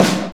RX GUNSHOT S.wav